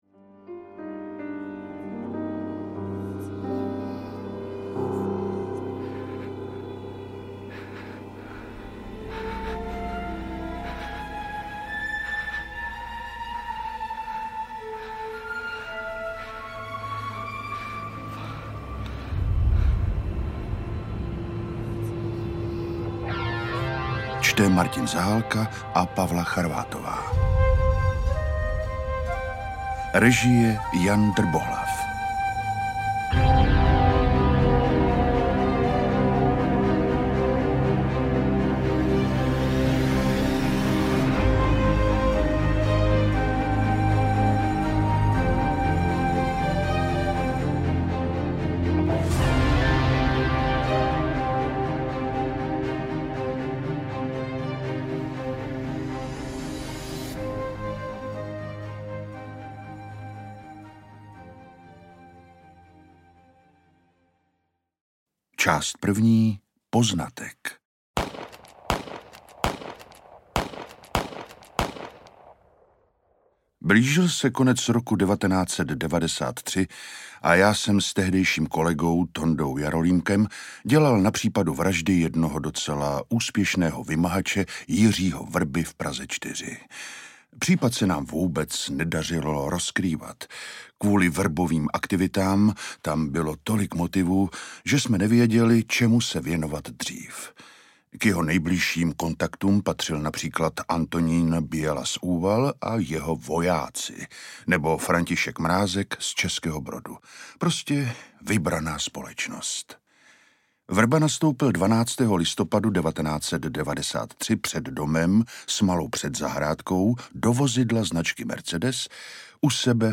Orlík audiokniha
Ukázka z knihy